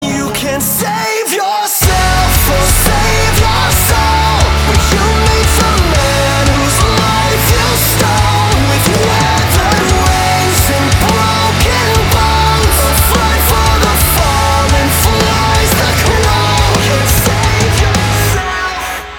громкие
Драйвовые
Metalcore
Alternative Metal
сильный голос